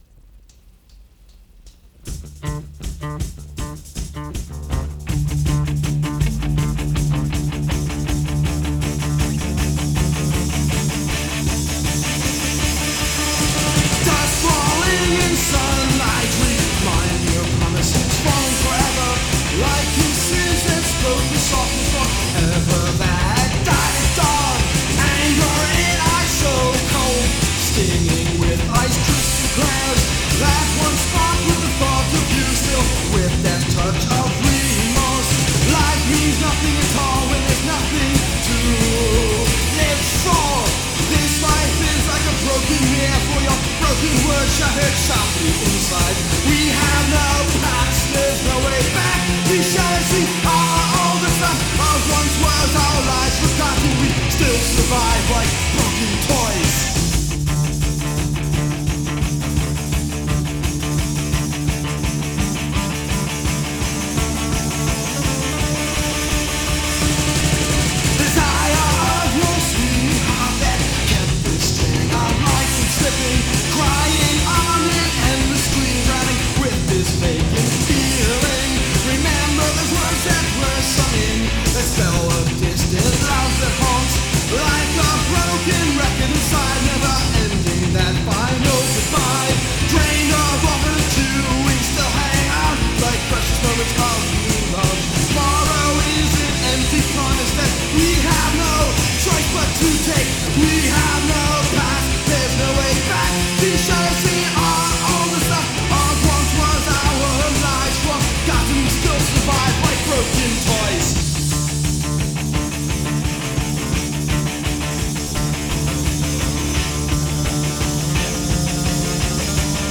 Vocals
Guitar
Drums